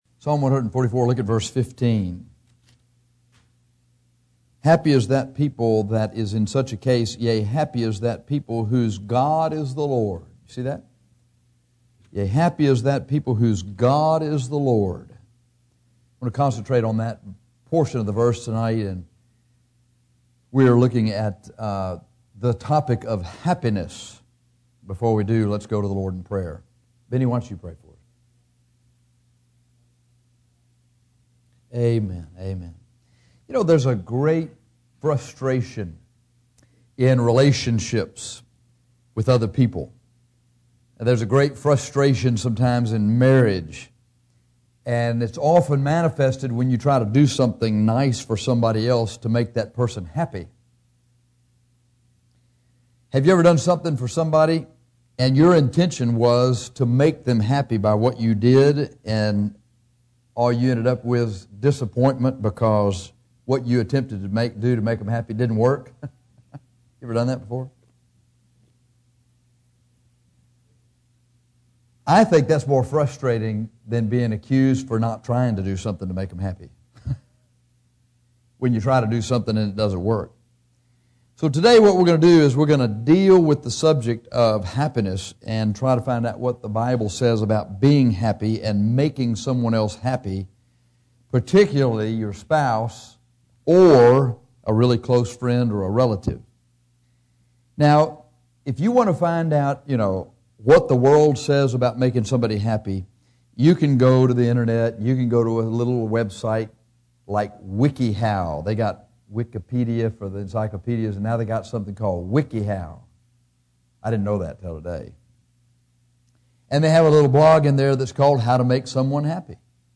Happiness - Bible Believers Baptist Church